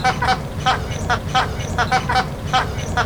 Bird Sounds
3. Chilean Flamingo